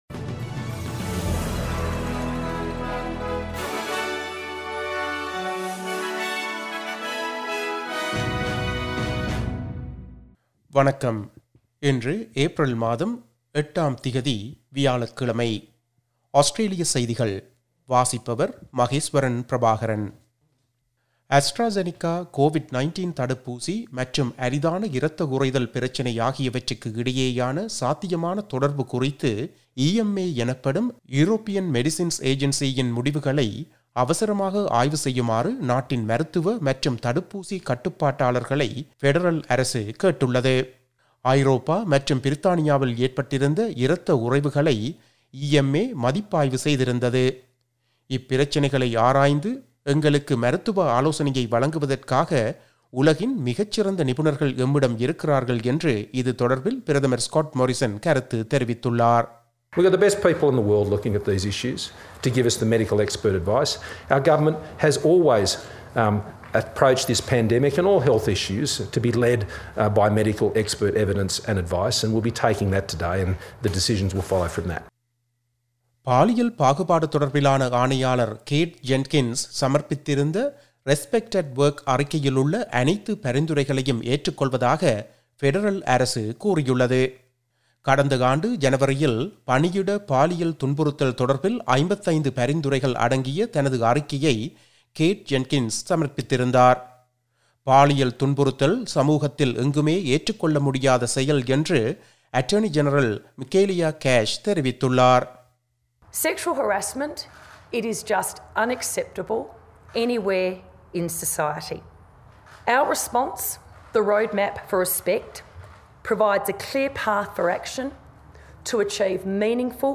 Australian news bulletin for Thursday 08 April 2021.